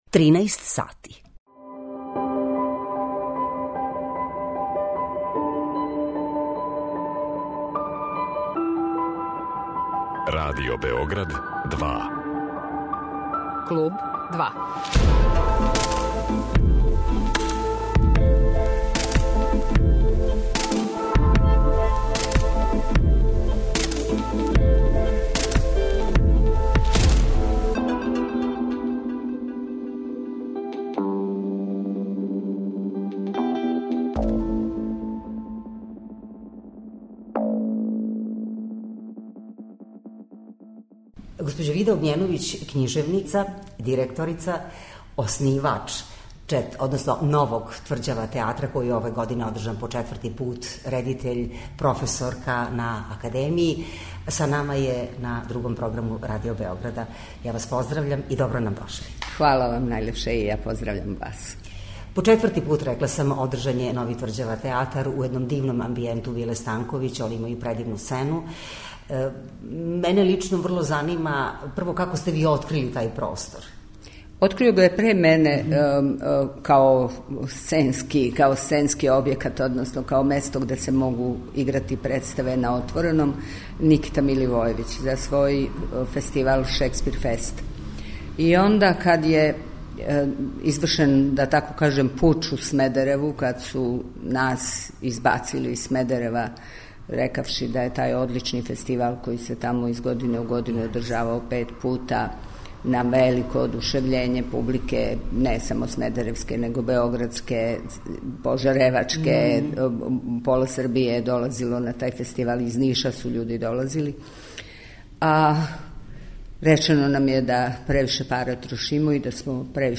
Гошћа емисија 'Клуб 2' је књижевница, професорка и редитељка Вида Огњеновић.